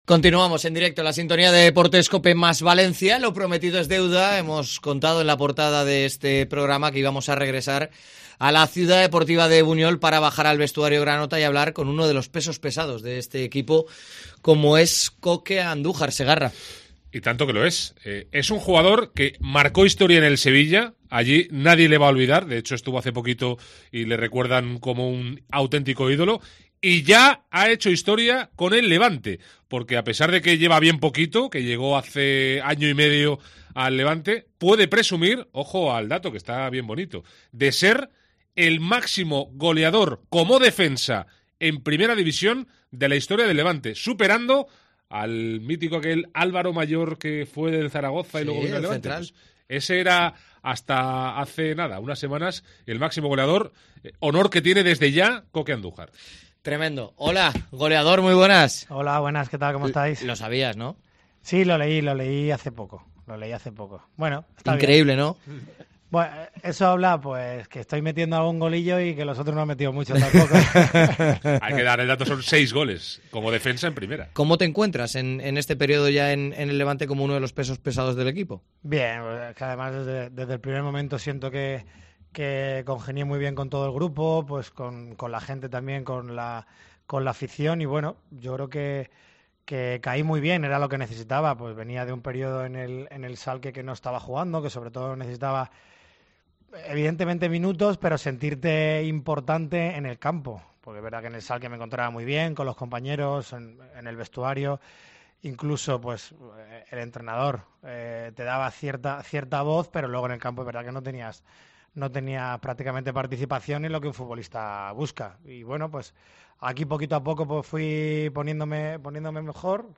Uno de los capitanes del Levante hace balance en COPE VALENCIA de las diez últimas jornadas.
ENTREVISTA COKE